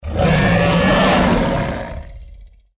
Âm thanh tiếng gầm rú của Rồng